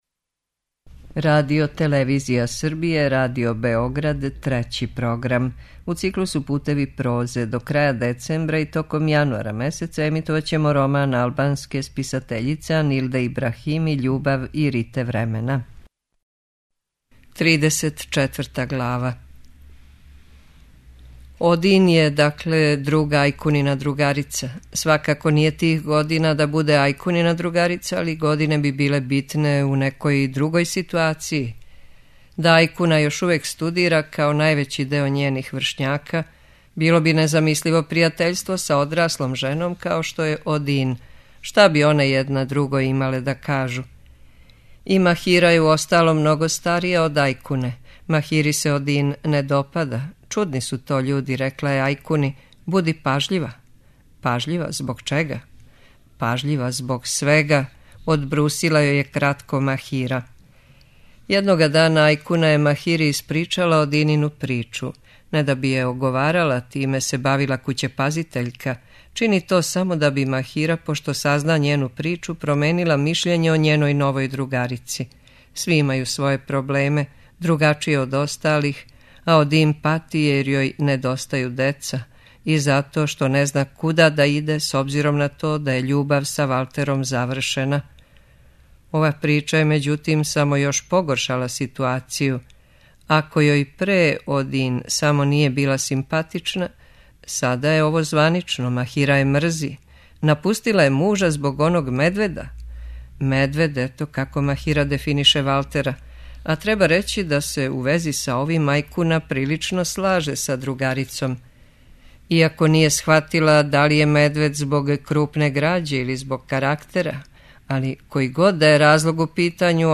Књига за слушање: Анилда Ибрахими: Љубав и рите времена (20)